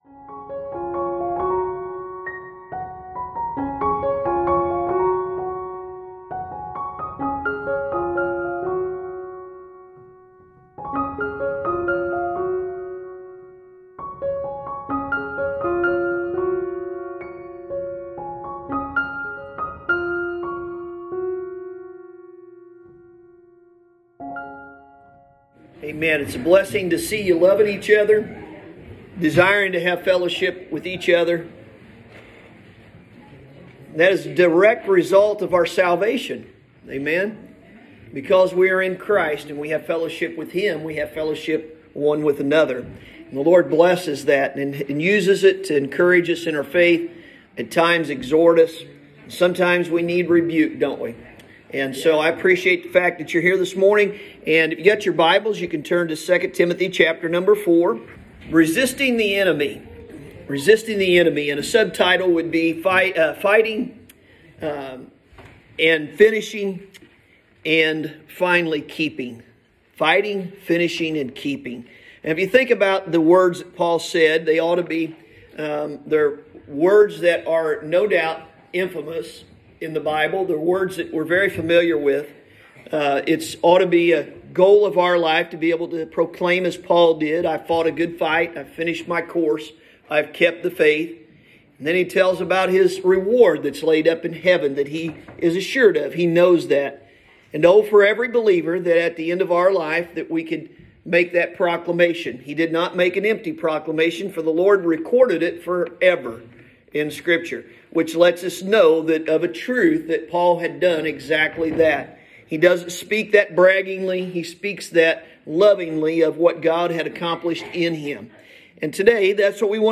Sunday Morning – March 14th, 2021